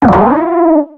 Cries
SAWSBUCK.ogg